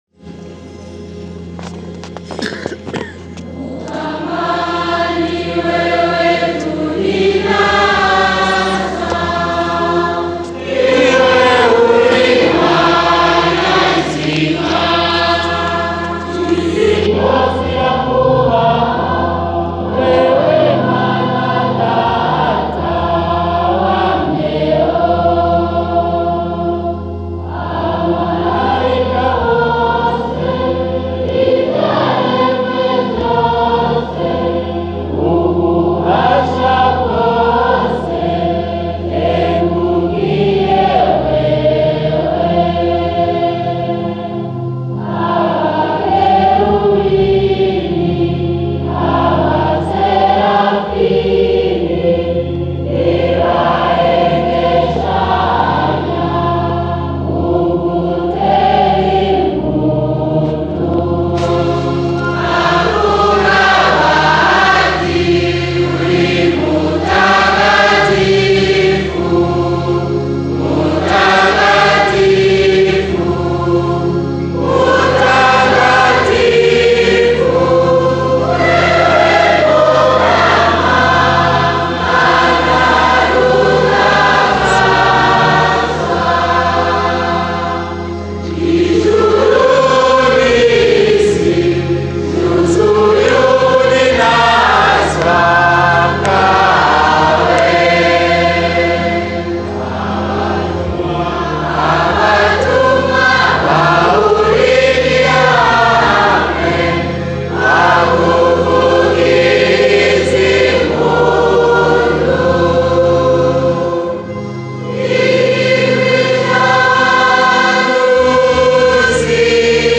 Avec toute l’Eglise, chantons cette louange de Dieu: le Te Deum laudamus